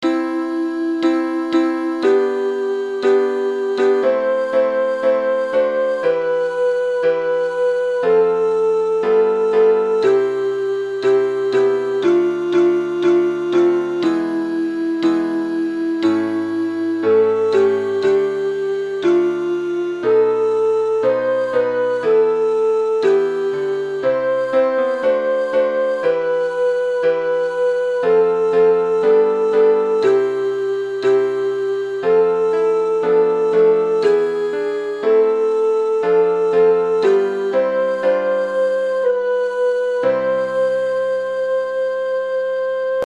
Signore-Delle-Cime-Soprano.mp3